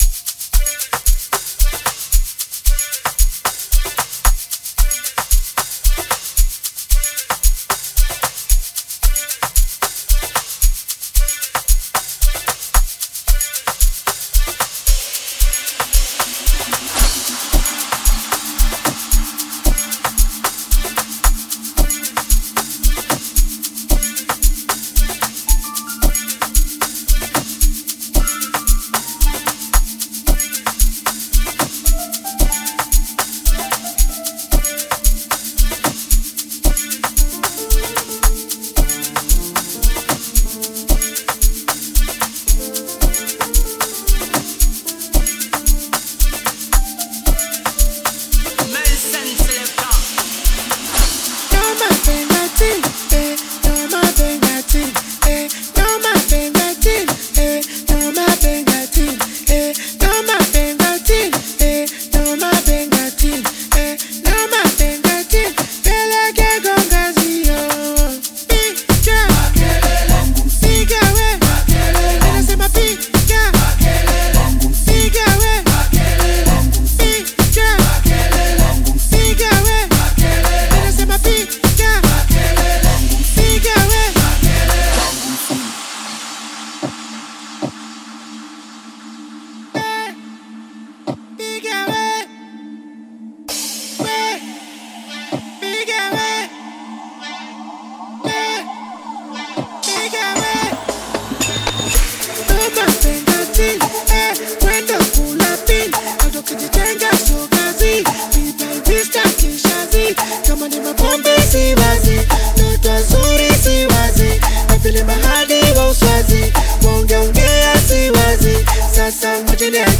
Tanzanian Bongo Flava
Bongo Flava song
Amapiano style
infectious rhythm and lively melodies